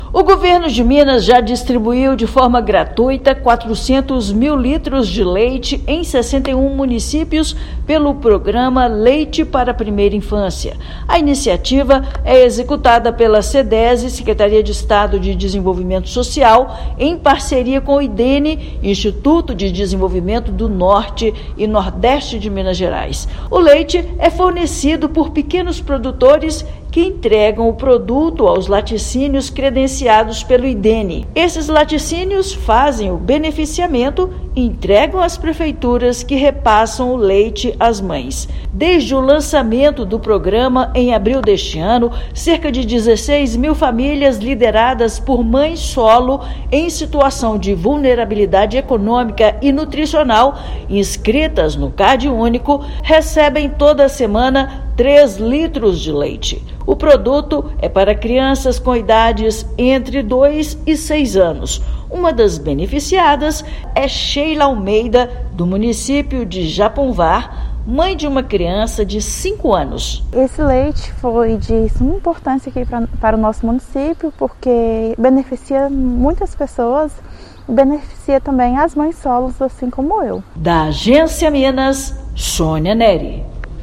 Programa desenvolvido pela Sedese e Idene já atendeu 16 mil famílias do Norte e Nordeste do estado. Ouça matéria de rádio.